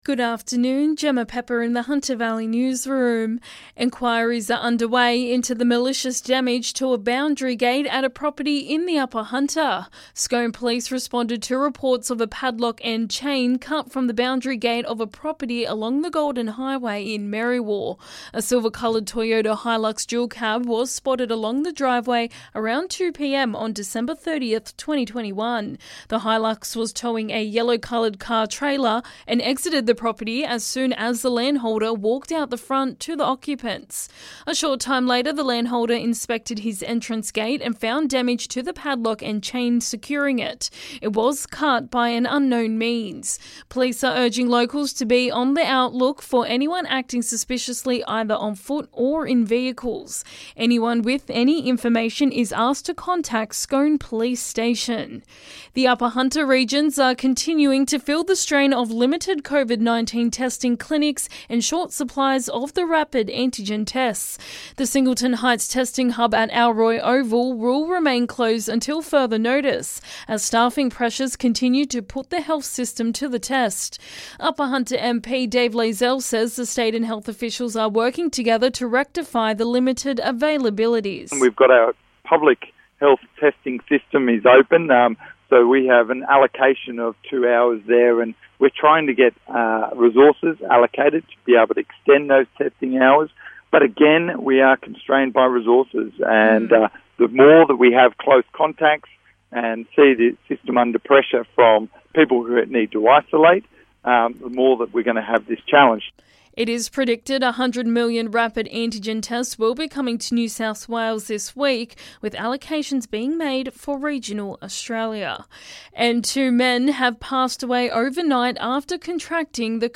LISTEN: Hunter Valley Local News Headlines 10/01/2022